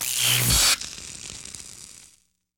shock.wav